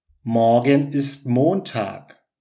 มอร์-เก็น อิส(ท) โมน-ทาก